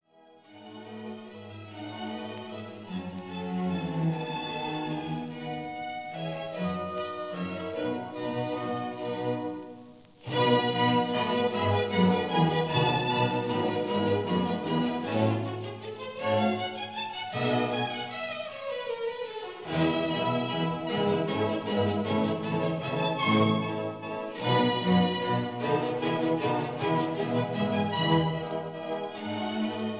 in E flat major
recorded in 1928